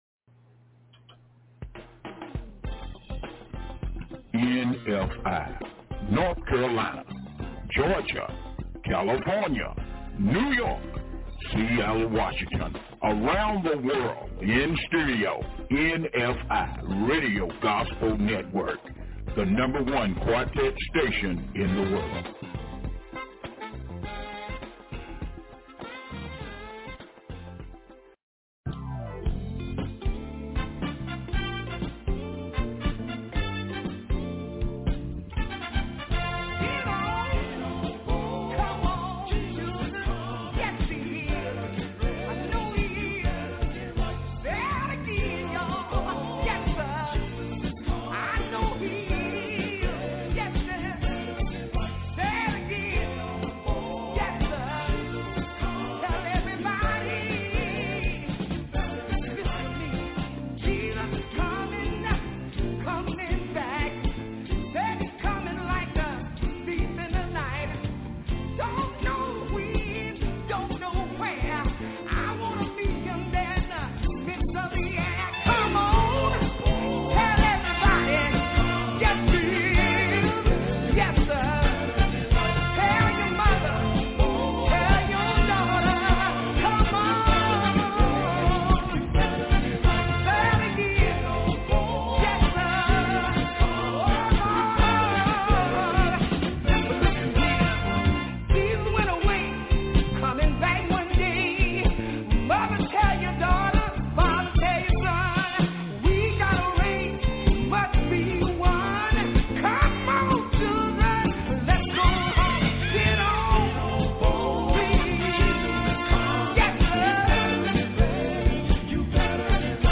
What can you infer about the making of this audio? It's Thursday November 28 2024 LIVE 8:00am til 11:00am